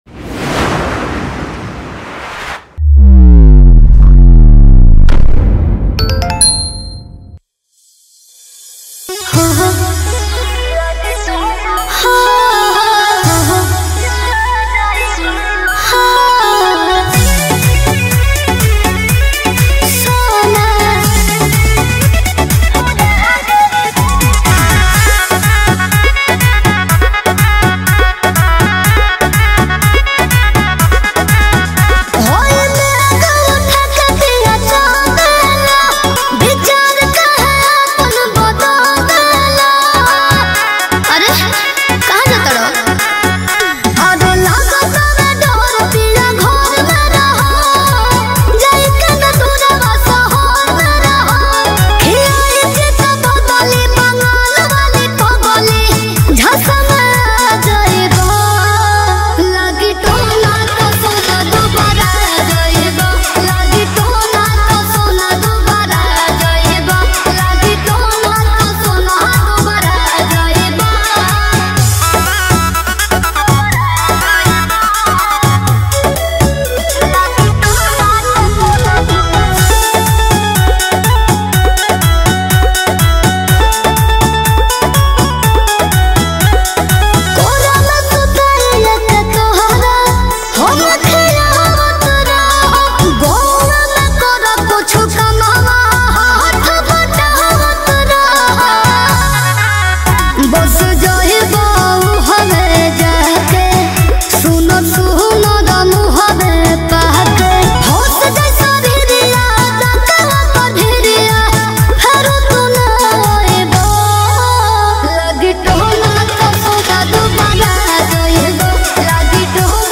Releted Files Of New Bhojpuri Song 2025 Mp3 Download